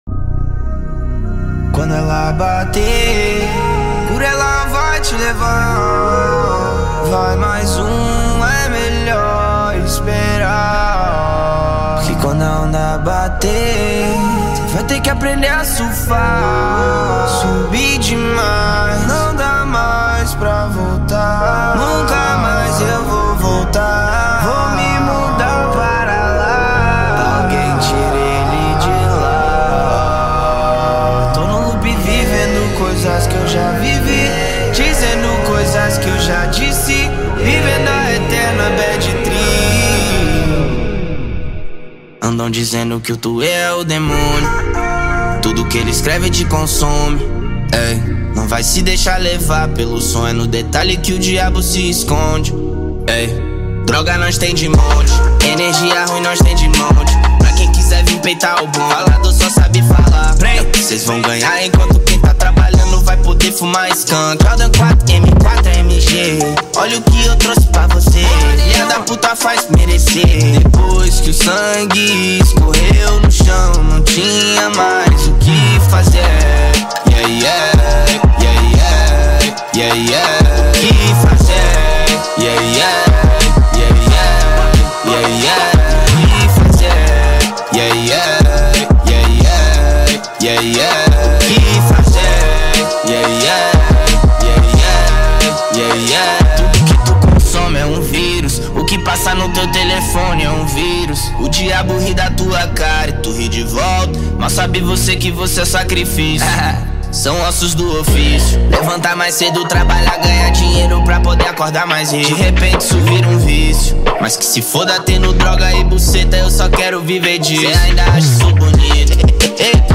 2024-04-10 19:14:26 Gênero: Trap Views